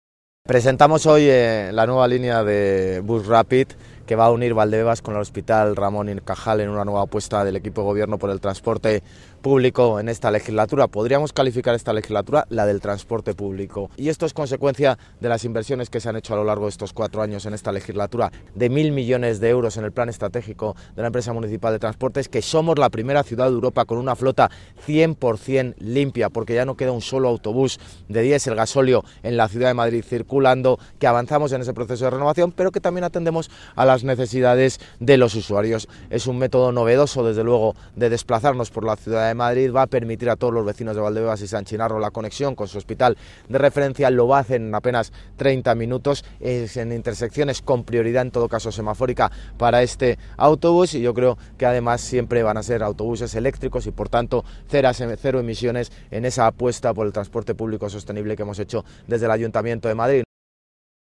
El acto de presentación ha tenido lugar en la avenida de las Fuerzas Armadas, en Valdebebas, en un tramo del trazado donde ya se están ejecutando las obras para la implantación de este BRT -por sus siglas en inglés Bus Rapid Transit-.